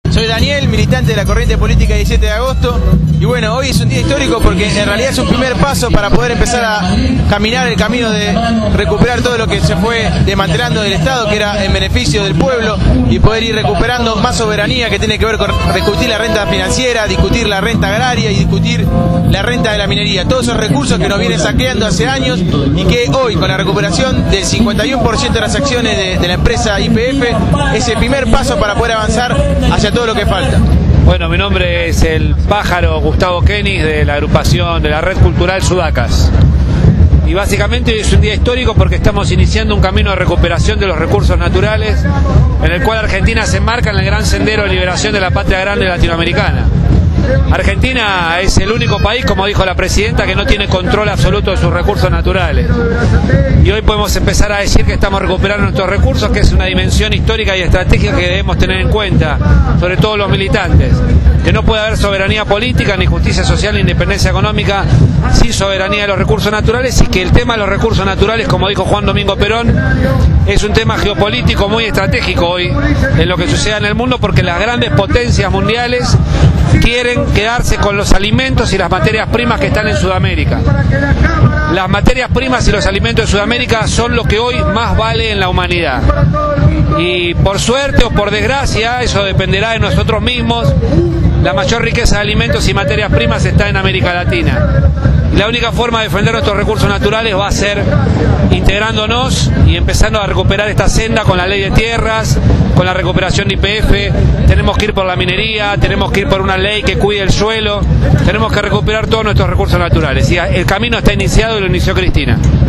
En una jornada histórica, en la concentración frente al Congreso en las últimas horas antes de la votación, compañeros/as de diferentes fuerzas políticas y sindicales dejaron sus palabras en Radio Gráfica.